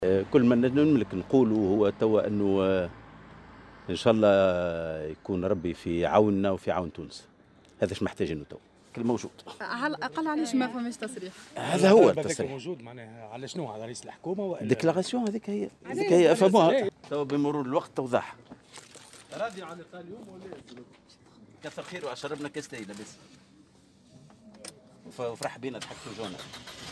وقال في تصريح إعلامي مقتضب اثر اجتماعه برئيس الحكومة المُكلف الحبيب الجملي " كان الله في عوننا وعون تونس".